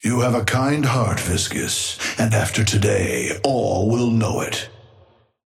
Amber Hand voice line - You have a kind heart, Viscous, and after today, all will know it.
Patron_male_ally_viscous_start_05.mp3